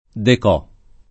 déco [fr. dek1] agg. e s. m.; inv. — es.: art déco [fr. ar dek1] (masch.) o arte déco; gusto déco; palazzina déco — in origine, forma abbr. per décoratif [dekorat&f] «decorativo», a proposito appunto di un’arte, uno stile, un gusto che furono in auge intorno al 1925 — raro l’adattam. it. decò [